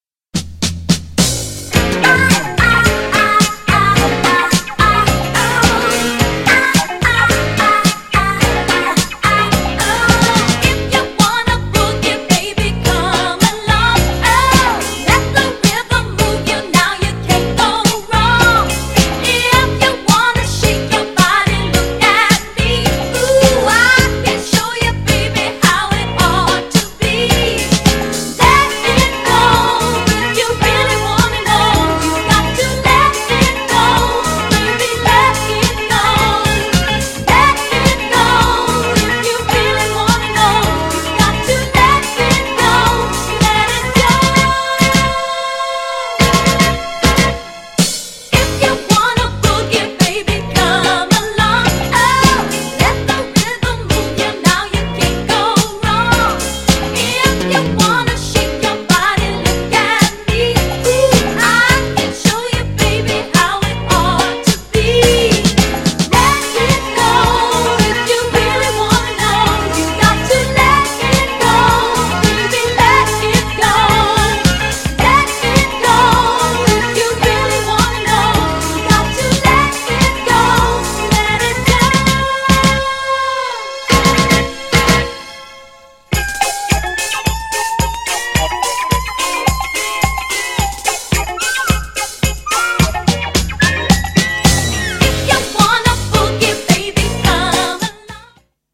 GENRE Dance Classic
BPM 106〜110BPM
HAPPY系サウンド # JAZZY
ピアノが美しい # ブラジリアン # メロディアス # ラテン # 洗練された